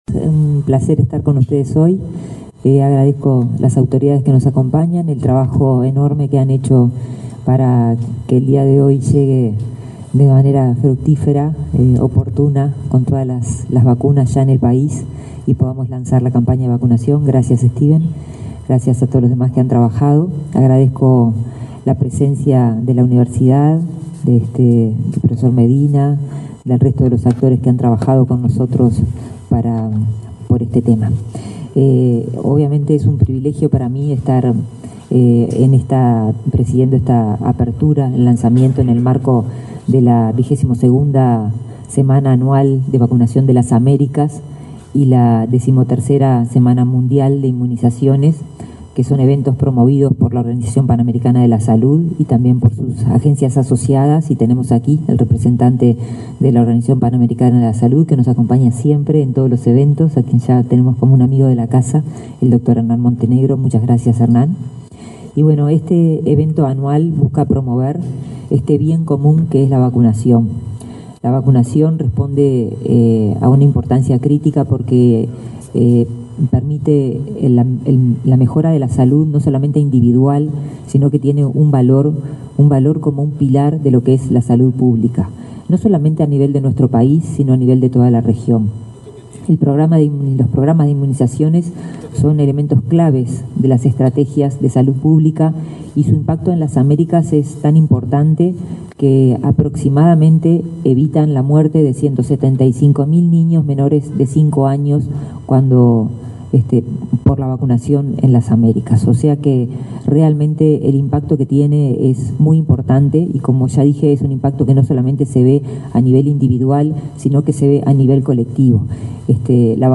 Palabras de autoridades en acto del MSP
Palabras de autoridades en acto del MSP 19/04/2024 Compartir Facebook Twitter Copiar enlace WhatsApp LinkedIn La titular y el subsecretario del Ministerio de Salud Pública, Karina Rando y José Luis Satdjian, respectivamente, y la directora general de Salud, Adriana Alfonso, realizaron, este viernes 19 en la sede de la cartera, el lanzamiento de la campaña antigripal 2024 y de la 22.ª Semana de Vacunación de las Américas.